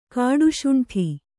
♪ kāḍu śuṇṭhi